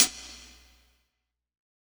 011_Lo-Fi Sunny Hi-Hat.wav